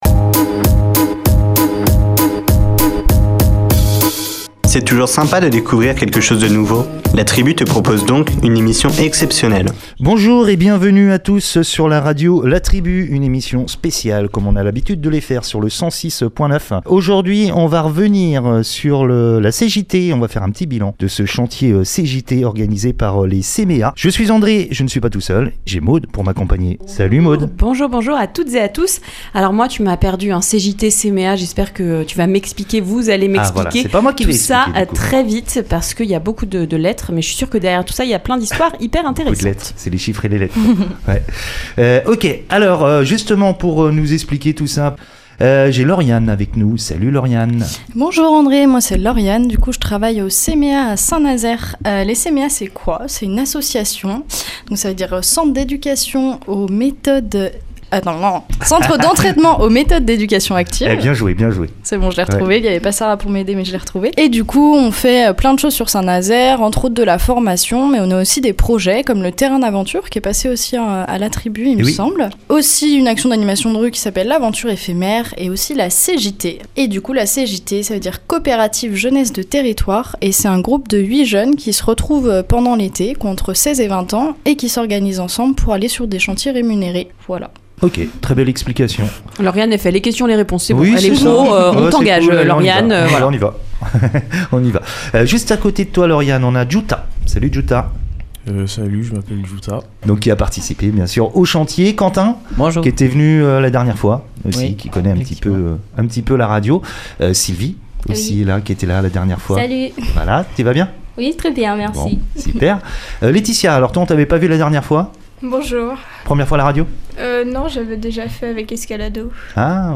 On fait le bilan de cette expérience avec les jeunes, ils nous présentent également l’ensemble de cette coopérative 2022.